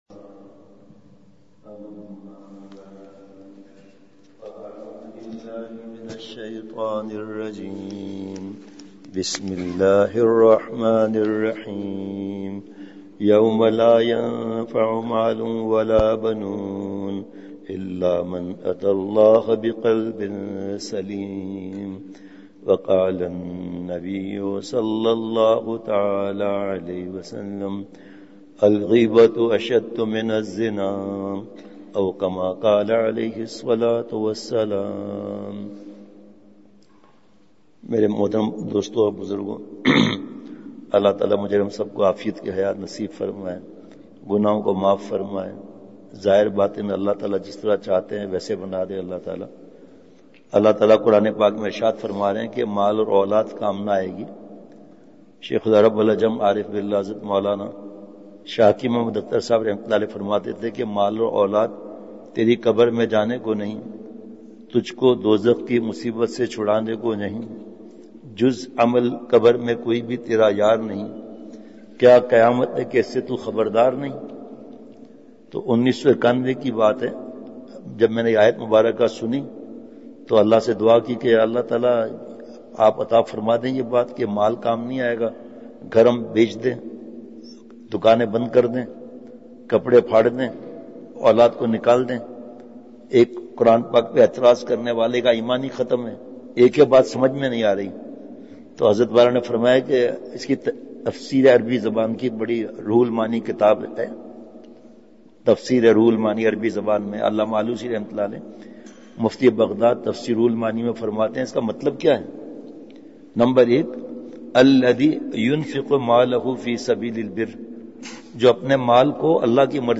سفر پنجاب مجلس بعد فجر ۲۵ / اکتوبر ۲۵ء:قلب سلیم !